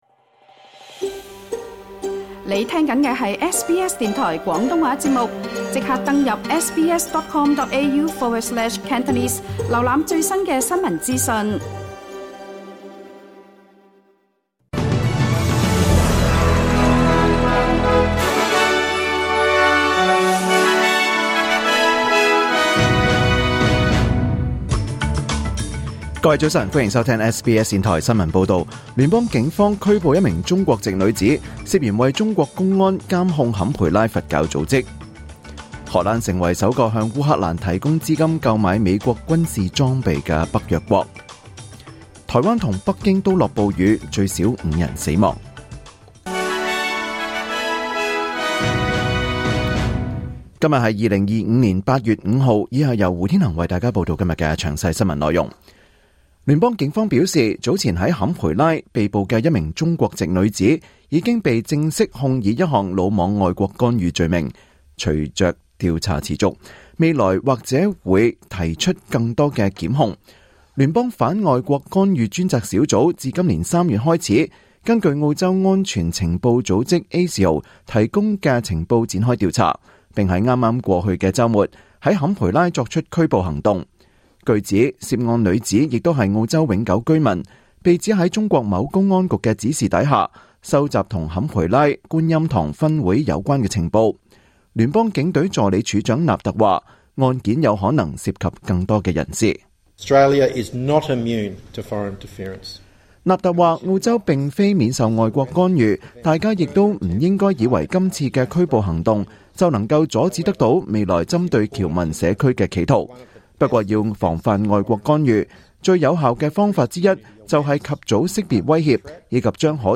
2025年8月5日SBS廣東話節目九點半新聞報道。